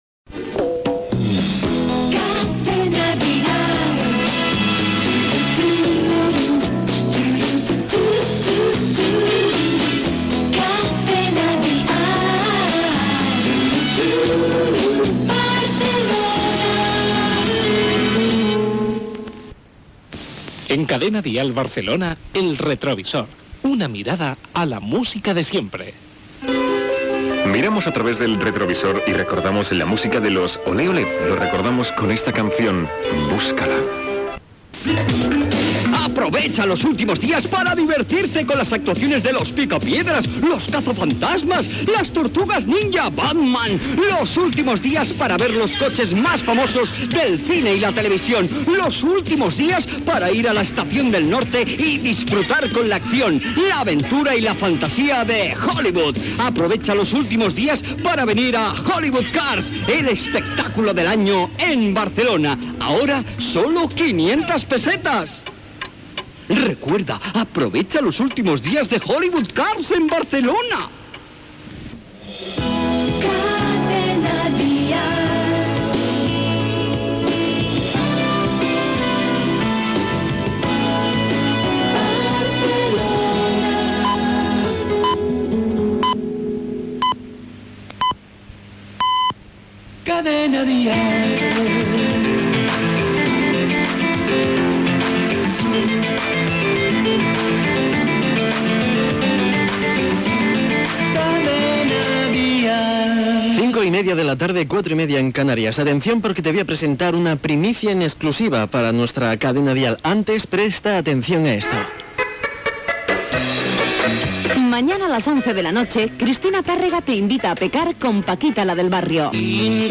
Indicatiu de l'emissora, identificació del programa, tema musical, publicitat, indicatiu de l'emissora, horàries, identificació de la cadena, hora, promoció del programa "La hora de las estrellas", indicatiu de l'emissora
FM